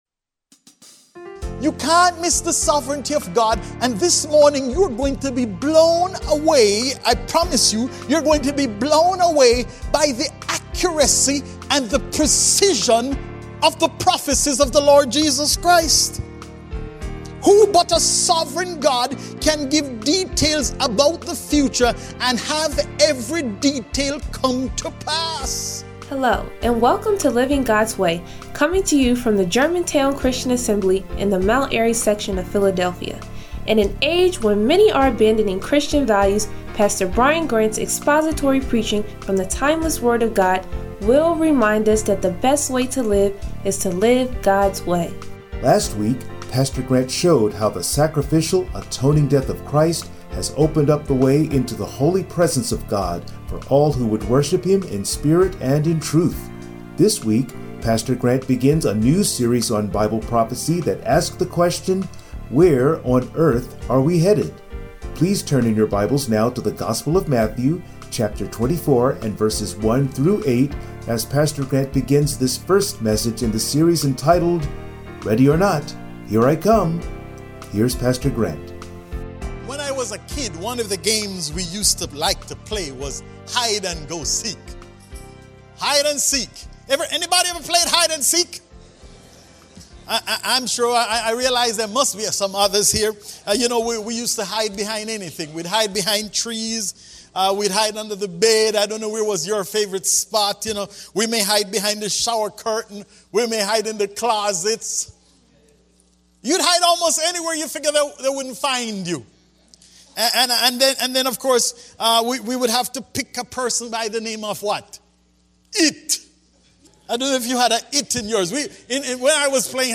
Bible Prophecy Service Type: Sunday Morning Preacher